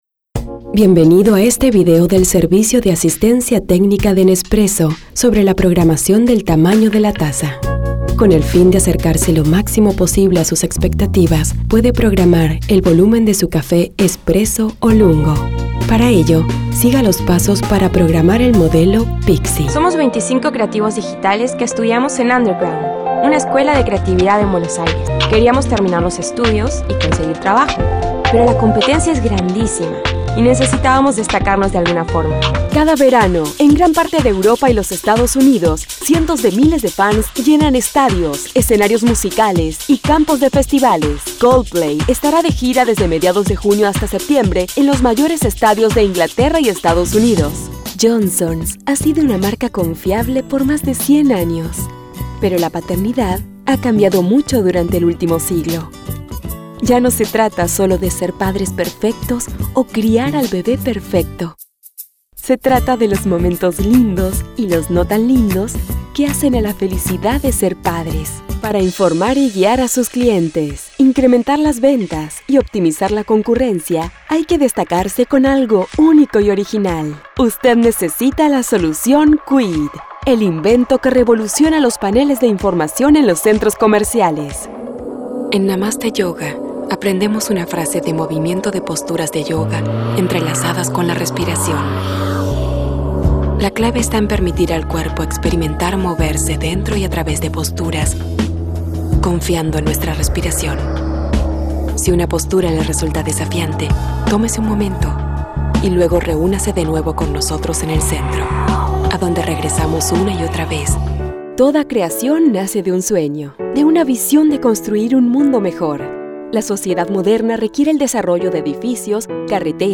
Female Voice Over, Dan Wachs Talent Agency.
Sincere, Conversational, Warm, Inspiring
Narration